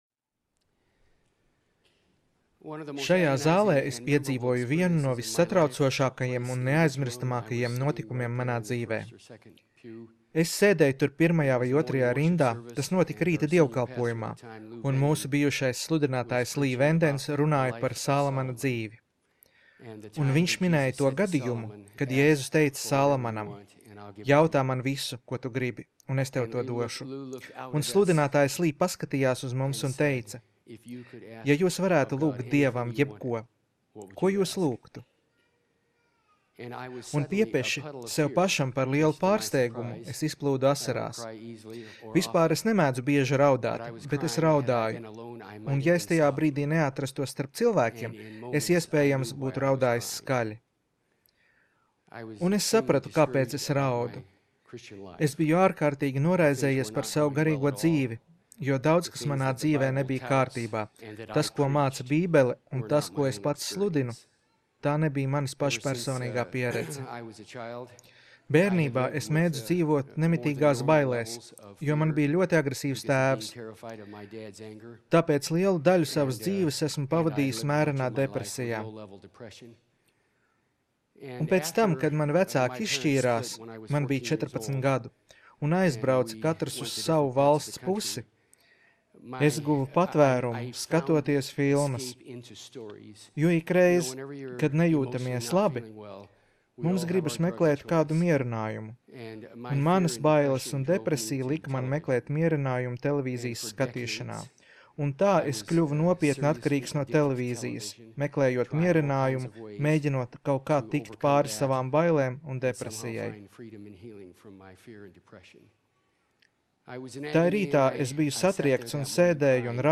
Skatīties video Seminārs - Kā pareizi nomirt... un dzīvot, lai par to stāstītu!